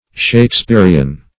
Shakespearean \Shake*spear"e*an\, a.